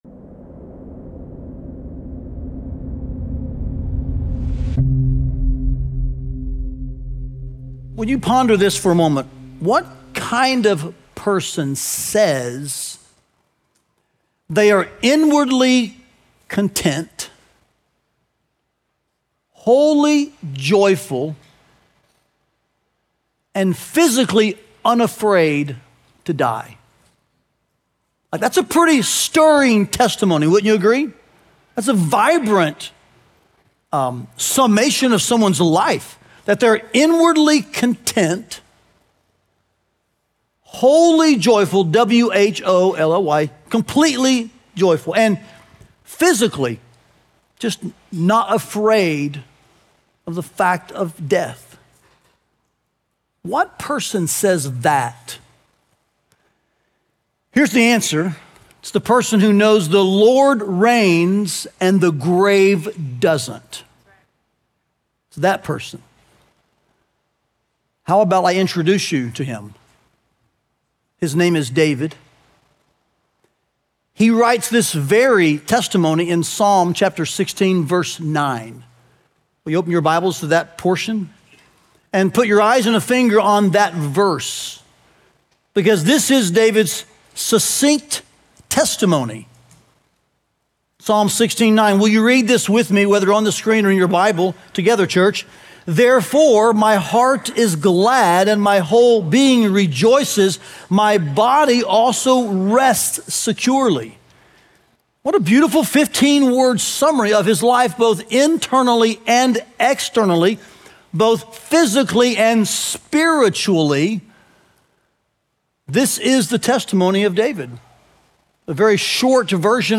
Listen to the final sermon of our Passion Week series “The Way of the Messiah.”